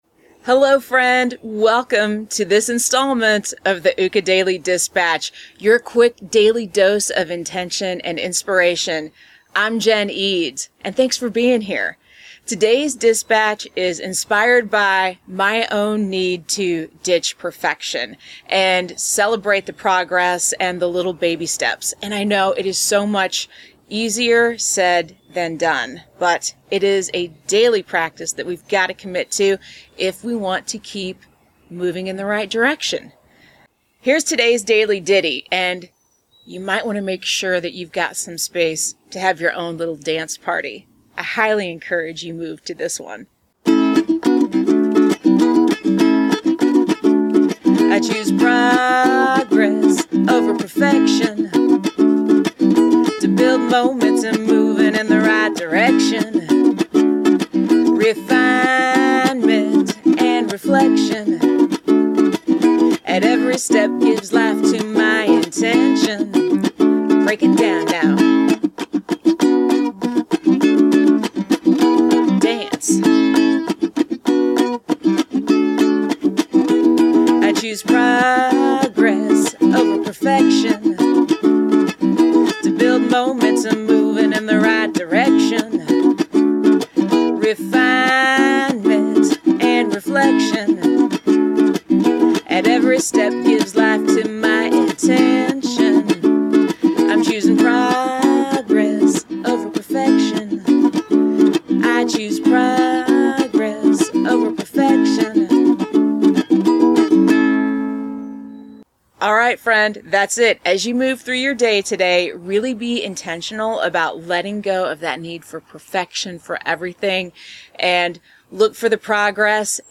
[:43] Song starts